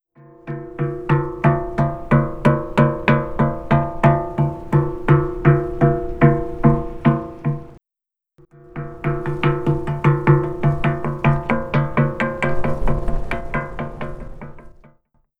Syngesteinen ved Bjørndalsnipa
Type: Klangstein
Lyden er dyp og metallisk, og tonehøyden varierer etter hvor man slår.
Bjorndalsnipa-lyd.wav